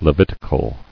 [Le·vit·i·cal]